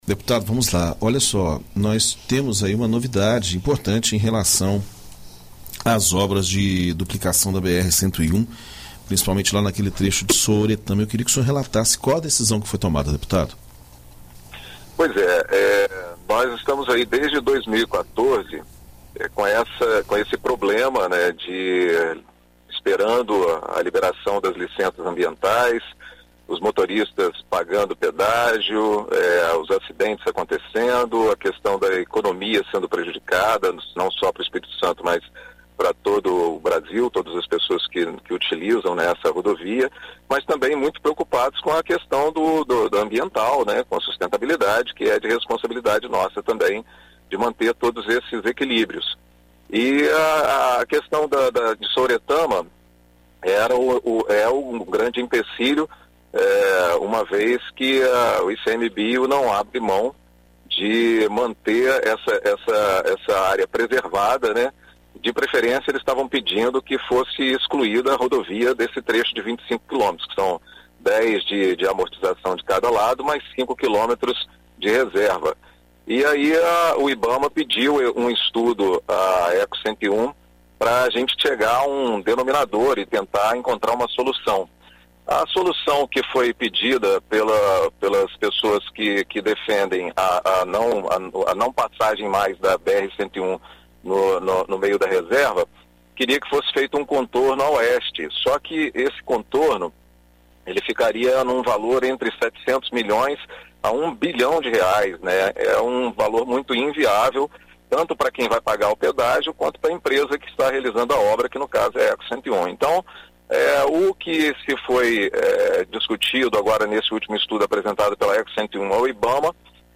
Em entrevista à BandNews FM Espírito Santo nesta sexta-feira (06), o deputado federal e presidente da Comissão Externa de Fiscalização responsável por fiscalizar o contrato de duplicação da rodovia, Ted Conti, conversa sobre o assunto.